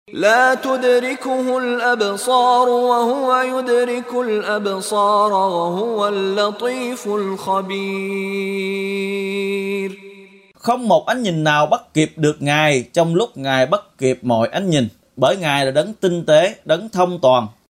Đọc ý nghĩa nội dung chương Al-An’am bằng tiếng Việt có đính kèm giọng xướ